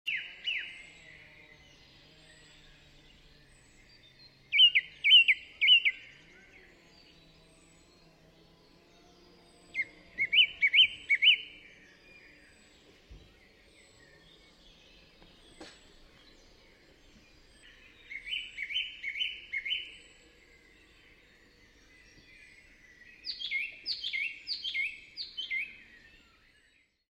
pirouesie-2025-grive.mp3